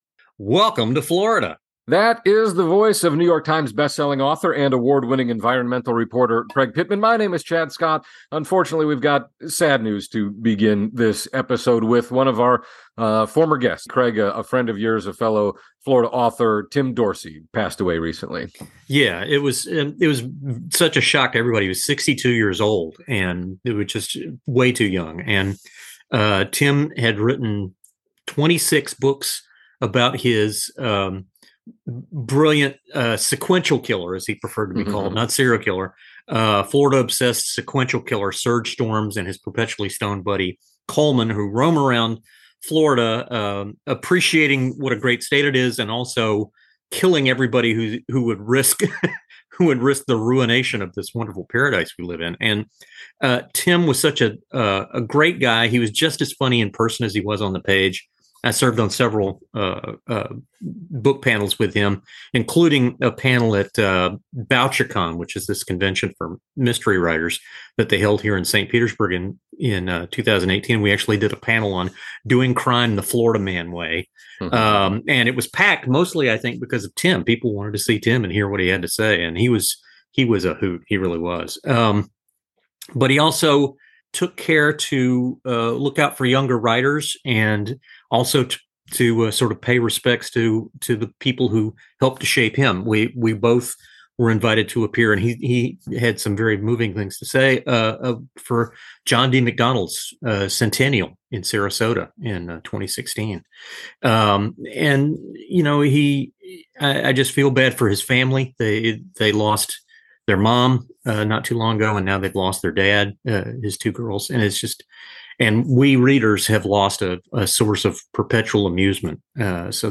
He's our guest this week.